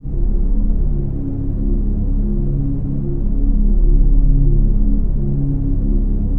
DM PAD1-20.wav